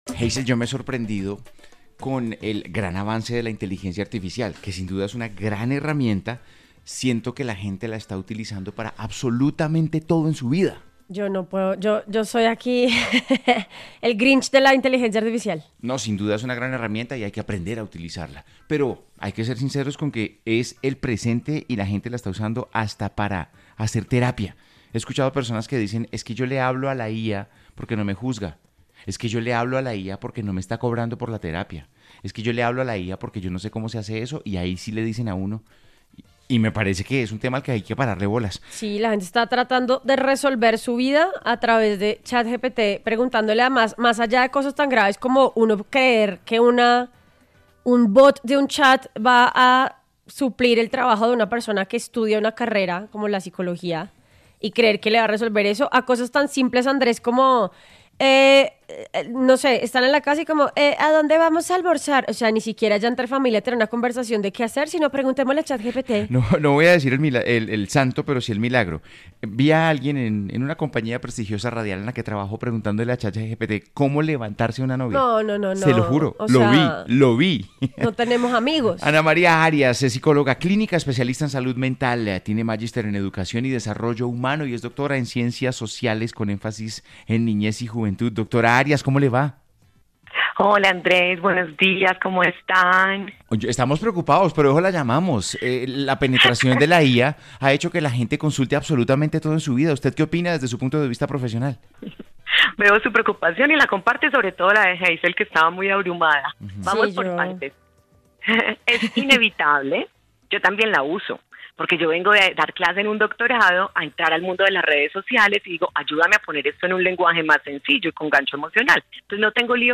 En entrevista con A vivir que son dos días